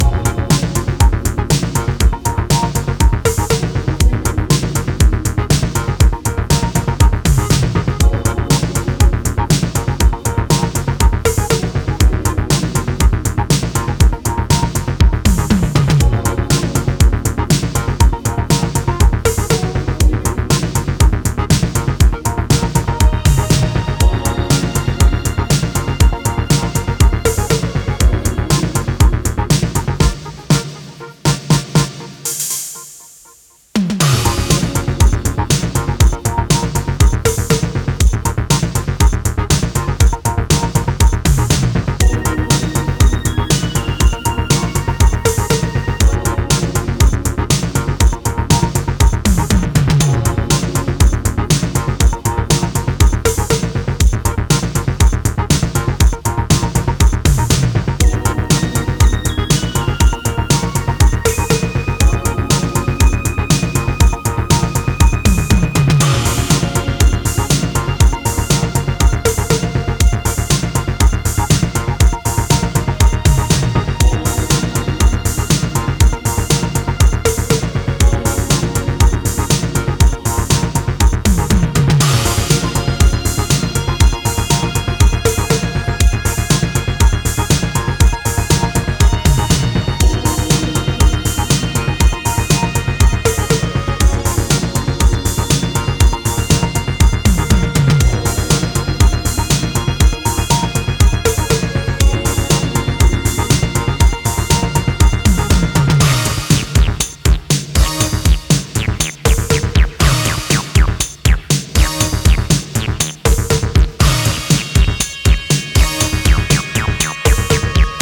シンセベースで重心を落としフロアライクに仕立てたB-1